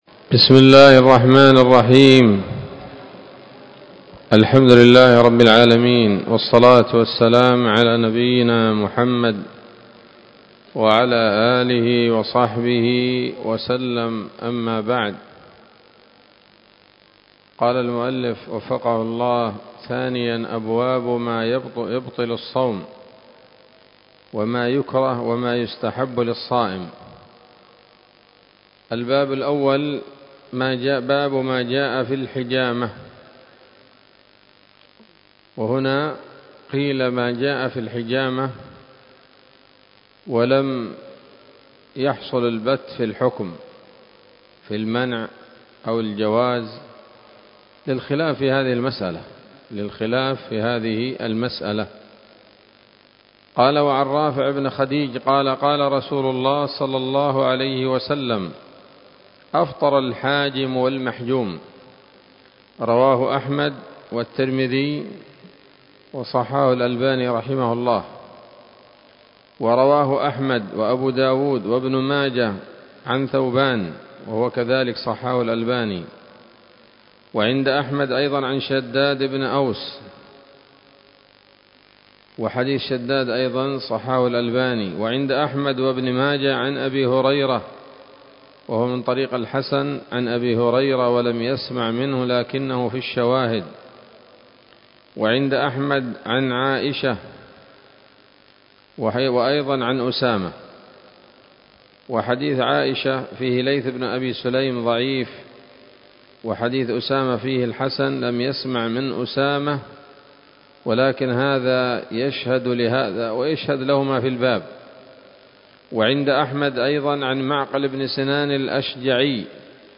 الدرس الخامس من كتاب الصيام من نثر الأزهار في ترتيب وتهذيب واختصار نيل الأوطار